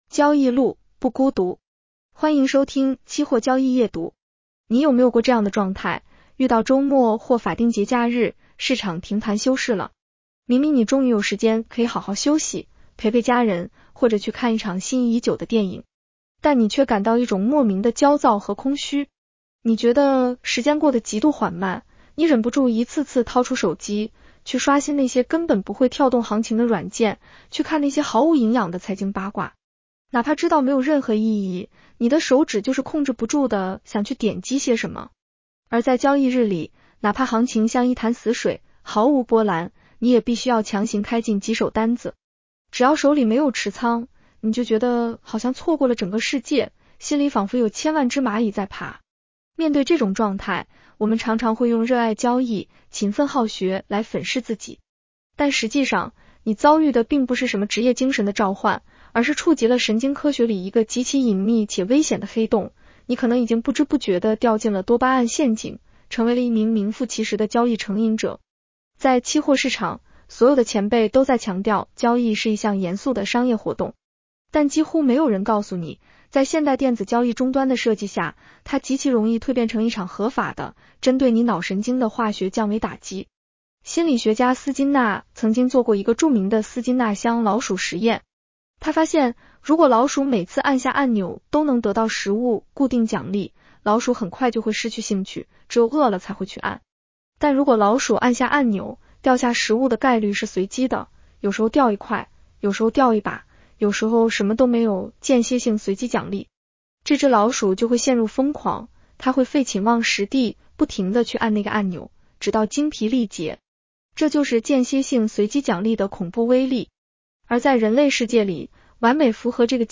（AI生成） 风险提示及免责条款：市场有风险，投资需谨慎。